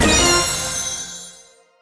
get_powerpoints_02v2.wav